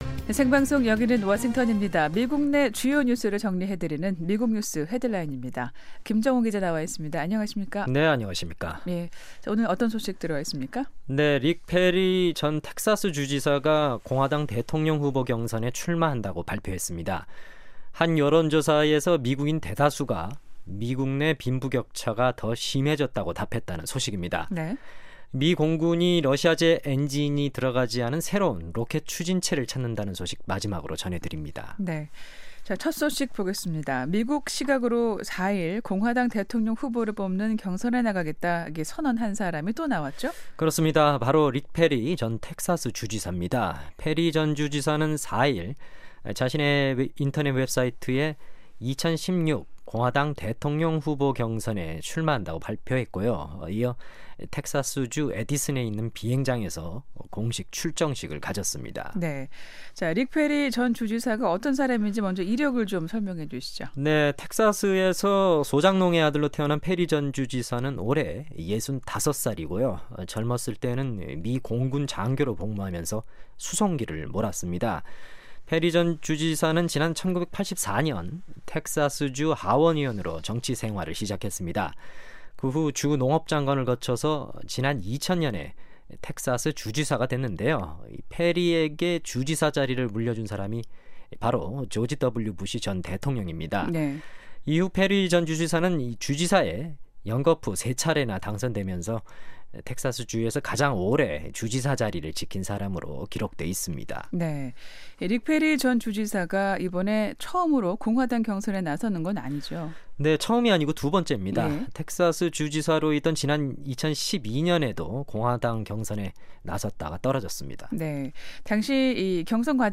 미국 내 주요 뉴스를 정리해 드리는 ‘미국 뉴스 헤드라인’입니다. 릭 페리 전 텍사스 주지사가 공화당 대통령 후보 경선에 출마한다고 발표했습니다. 한 여론조사에서 미국인 대다수가 미국 내 빈부 격차가 더 심해졌다고 답했다는 소식입니다.